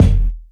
live_kick_.wav